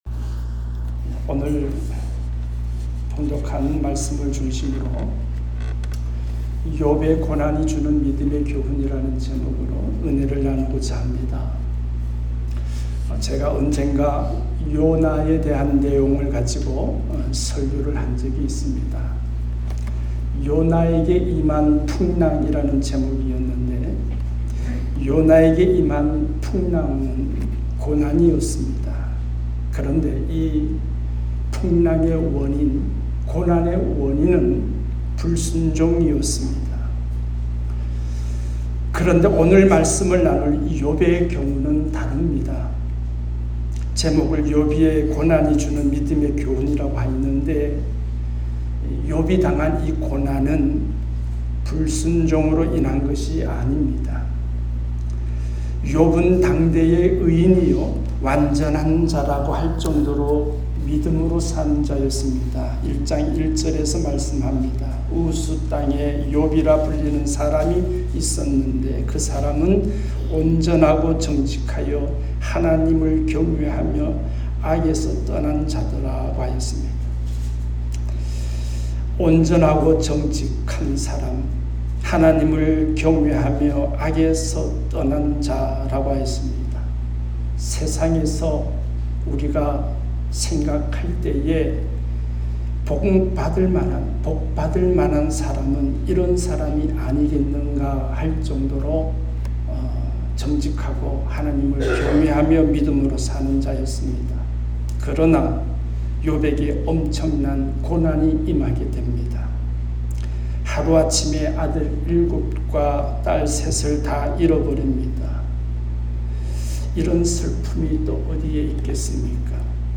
말씀